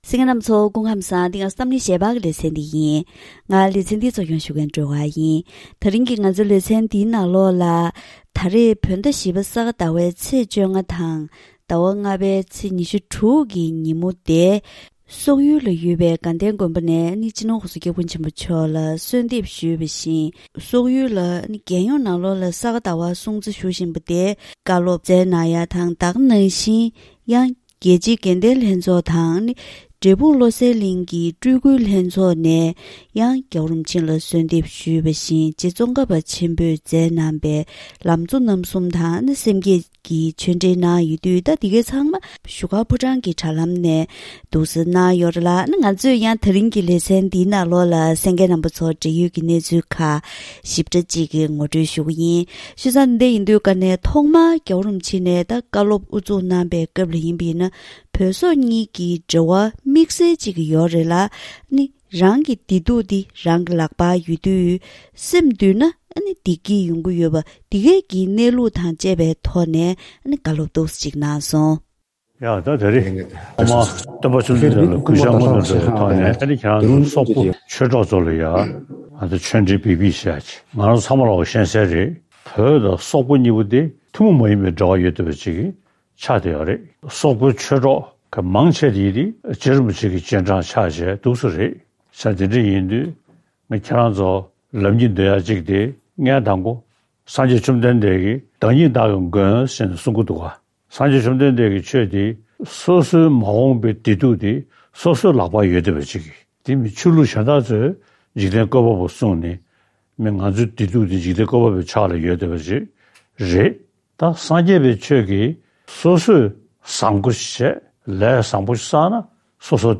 སྤྱི་ནོར་༧གོང་ས་༧སྐྱབས་མགོན་ཆེན་པོ་མཆོག་གིས་བཞུགས་སྒར་ཕོ་བྲང་ནས་དྲ་ལམ་ཐོག་སོག་ཡུལ་དུ་ས་ག་ཟླ་བའི་སྲུང་བརྩིར་བཀའ་སློབ་སྩལ་གནང་མཛད་པ།